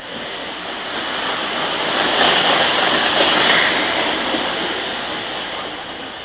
Electrictrain.mp3